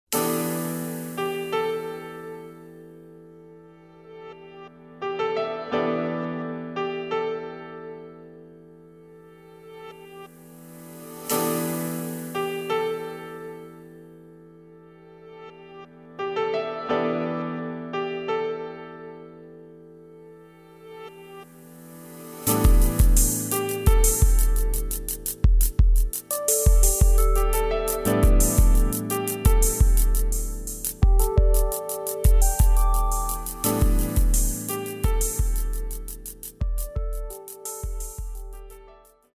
クリアな音が僕達の五感を刺激します。浮遊感のファンタジックマジック！！！